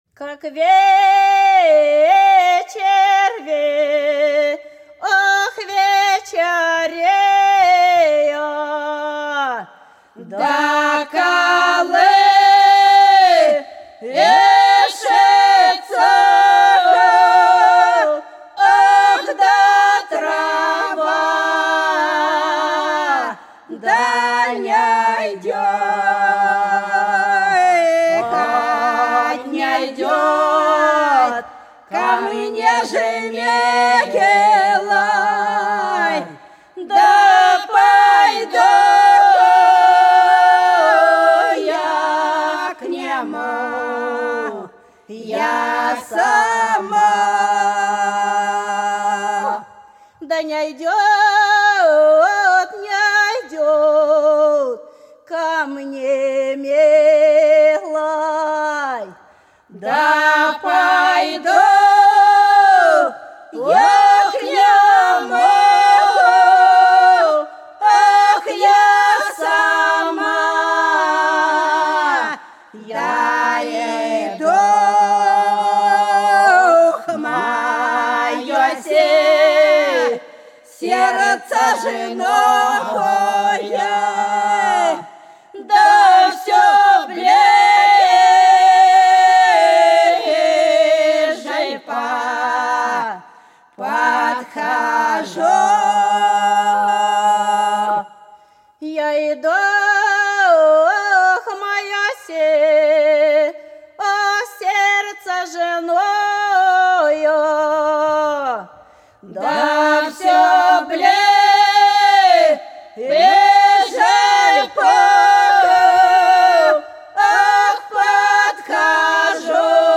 За речкою диво Как вечер вечереет - протяжная (с. Гвазда)
10_Как_вечер_вечереет_-_протяжная.mp3